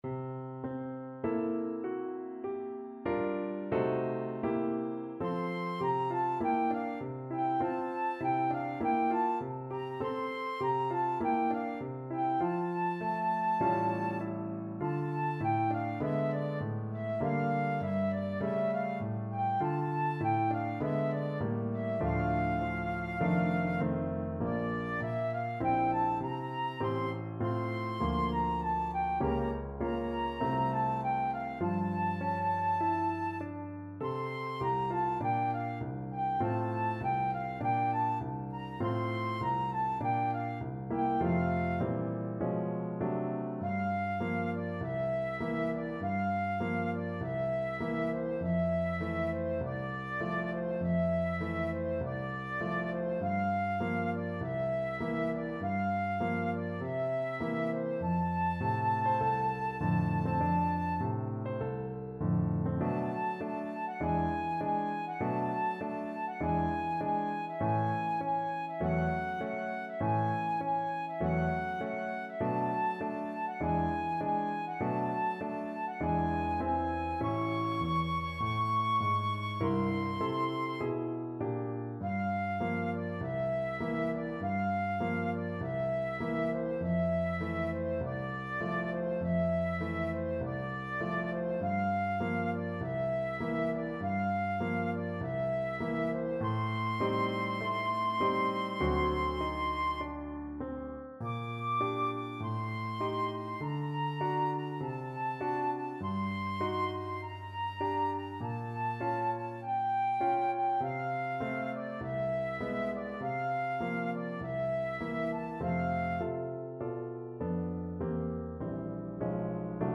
Moderato
4/4 (View more 4/4 Music)
Pop (View more Pop Flute Music)